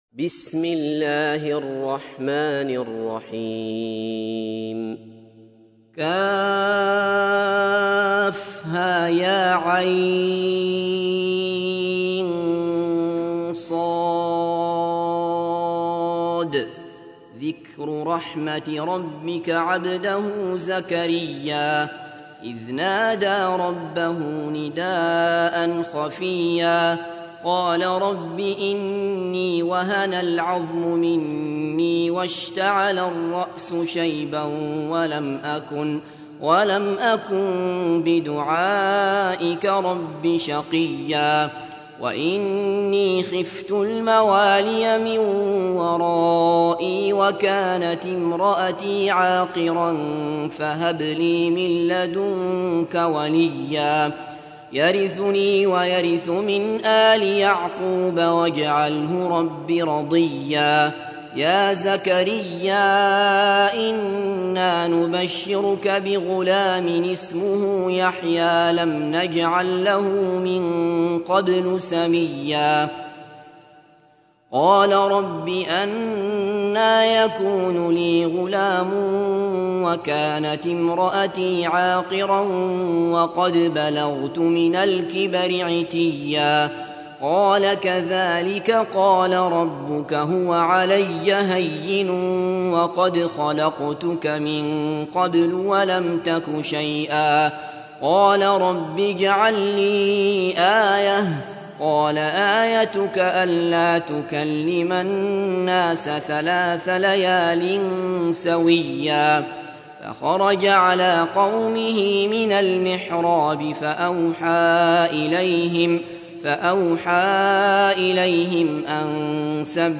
تحميل : 19. سورة مريم / القارئ عبد الله بصفر / القرآن الكريم / موقع يا حسين